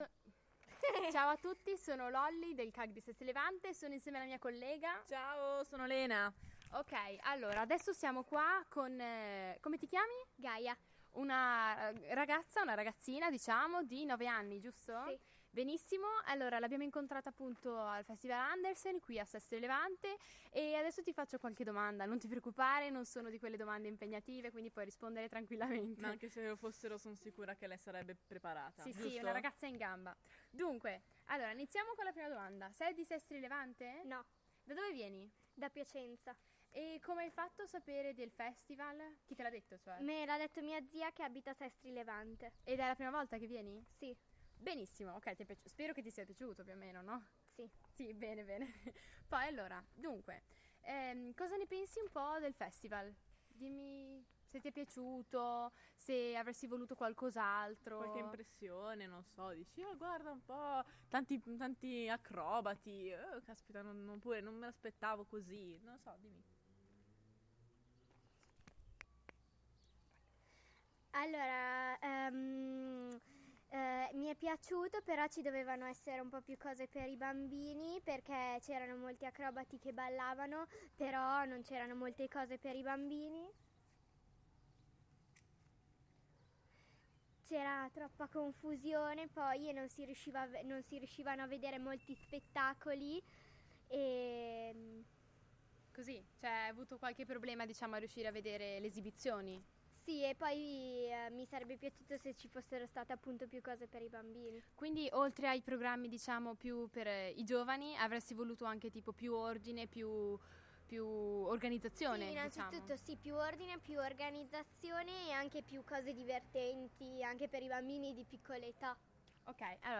Abbiamo fermato un passante durante il Festival Andersen per dar spazio anche alla voce dei turisti per loro eventuali considerazioni